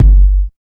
27 DEEP KICK.wav